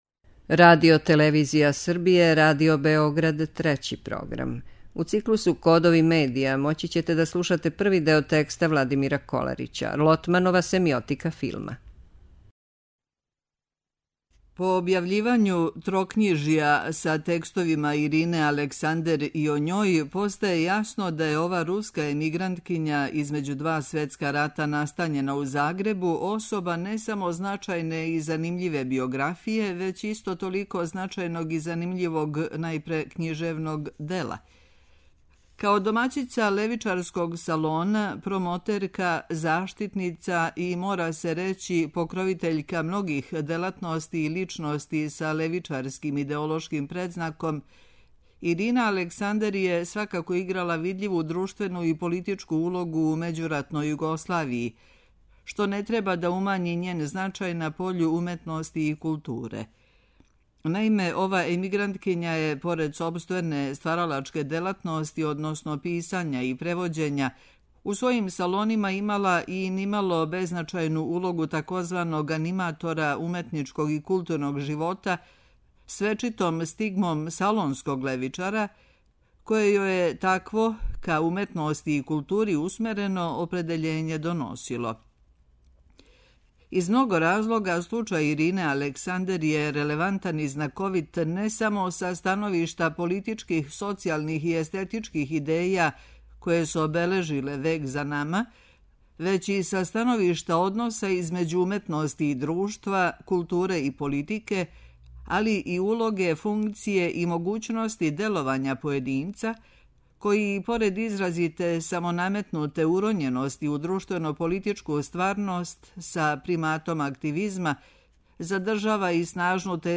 преузми : 28.07 MB Тема недеље Autor: Редакција Прва говорна емисија сваке вечери од понедељка до петка.